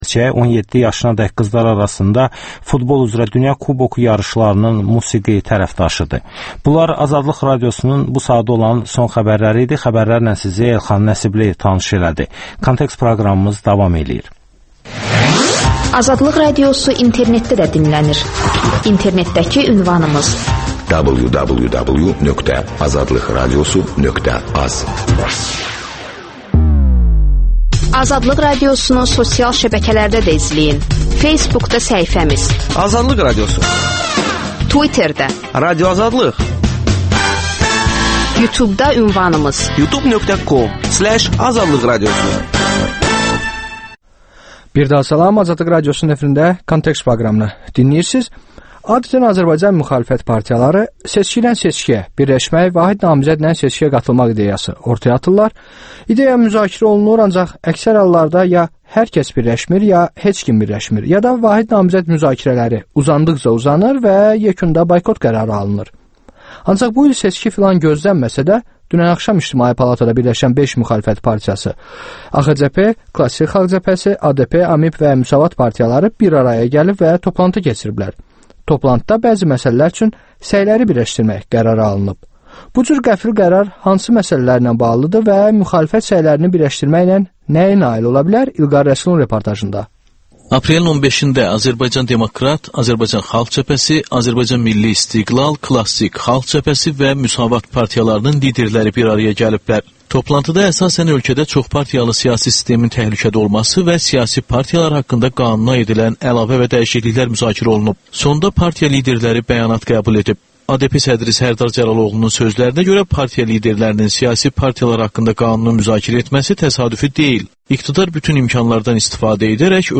Müsahibələr, hadisələrin müzakirəsi, təhlillər